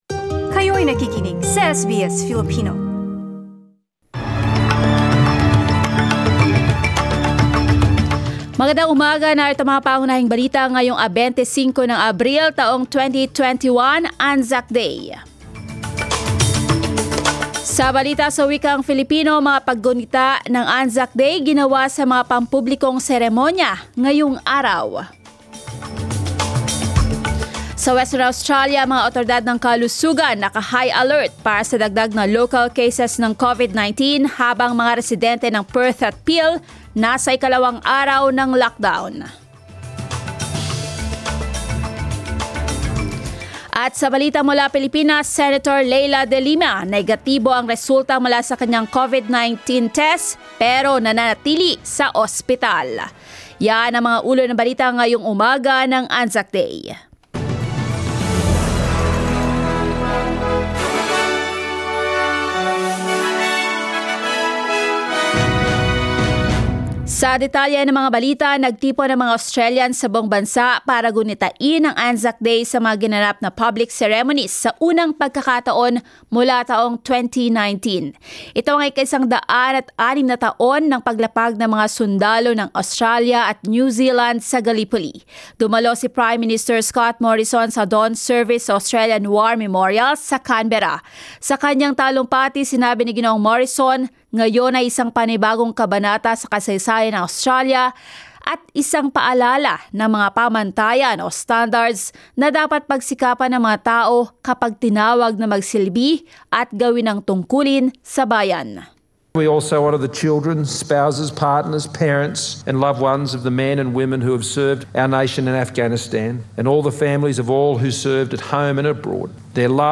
SBS News in Filipino, Sunday 25 April